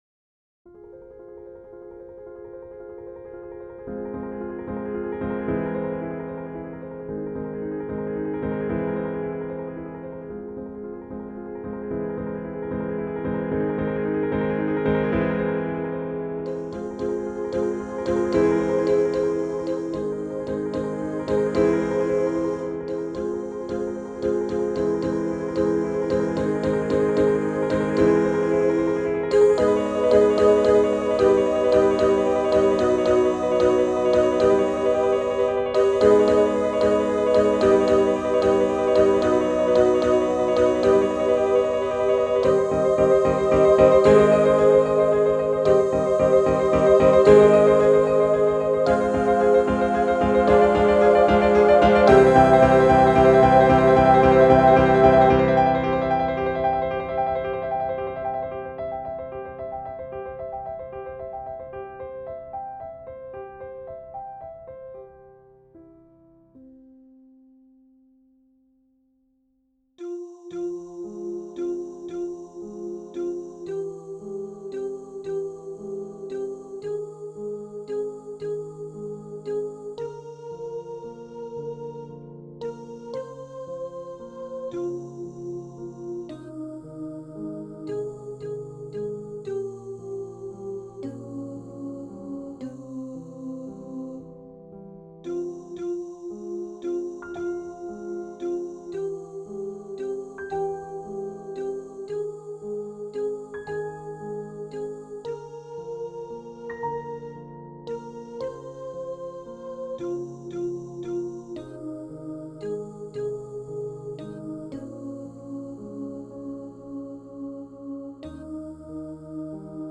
1   Computer Playback